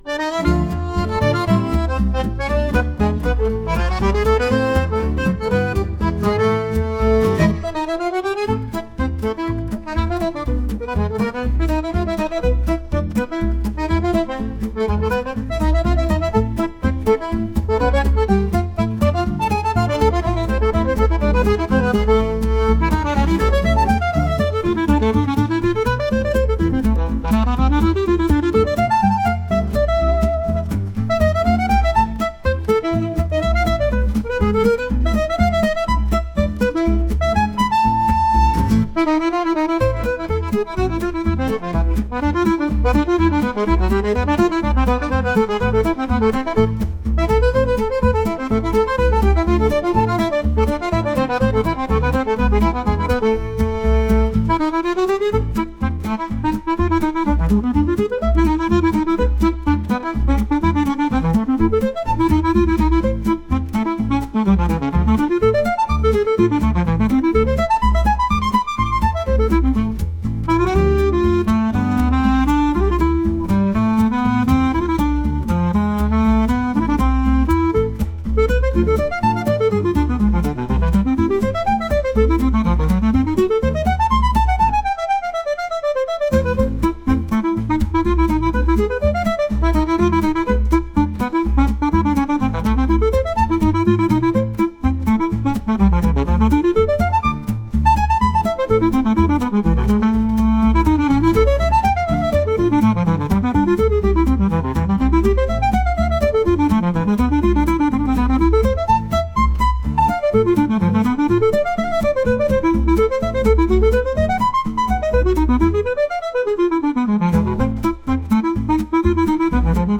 ワクワクするようなでも少し切ないようなアコーディオンの音楽です。